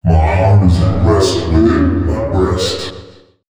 032 male.wav